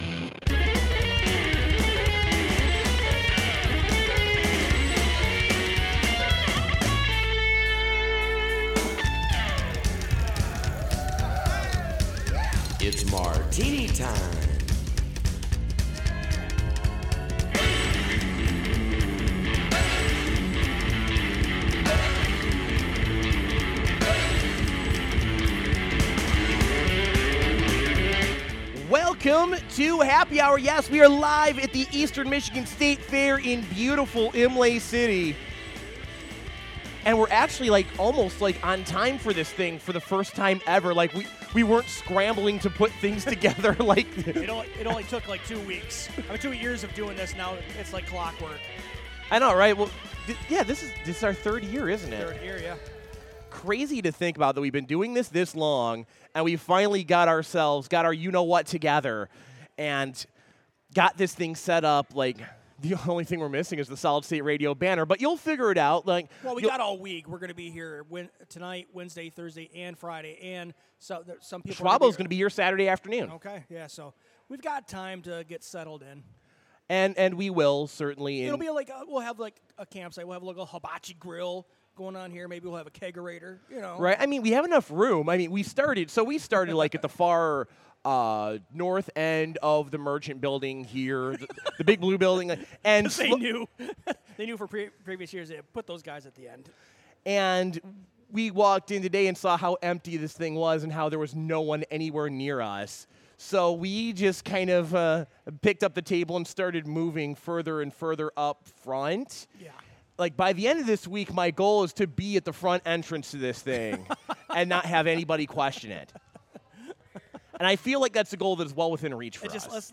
We’re live from the Eastern Michigan State Fair for the week!